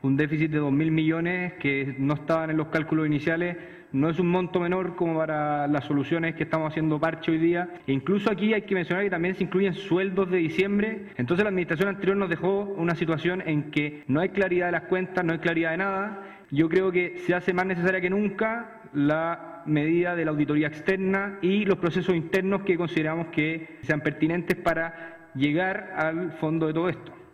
El concejal UDI, Luis Mackenna, dijo que es impresentable el desorden con el que la administración anterior dejó el municipio y emplazó a hacer una auditoría.